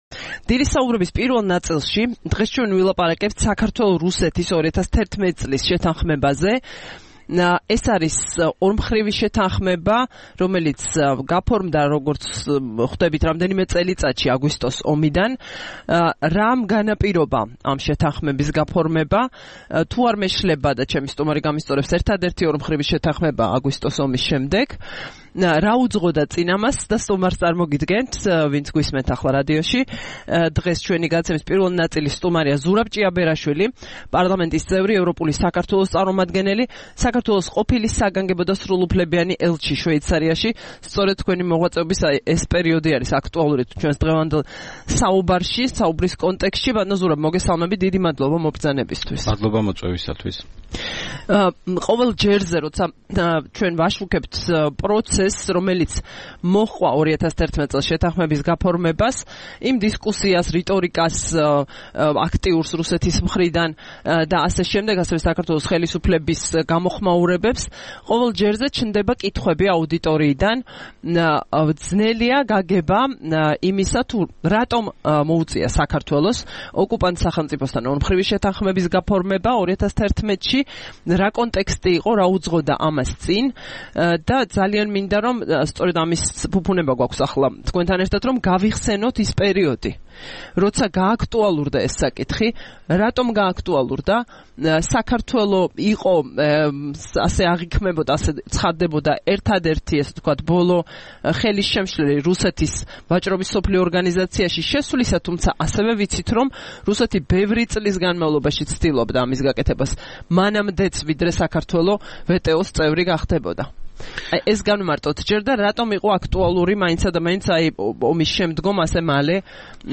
22 მაისს რადიო თავისუფლების "დილის საუბრების" სტუმარი იყო ზურაბ ჭიაბერაშვილი, პარლამენტის წევრი, საქართველოს ყოფილი ელჩი შვეიცარიაში.